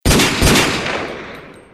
Sound effect
结局的两声枪响.mp3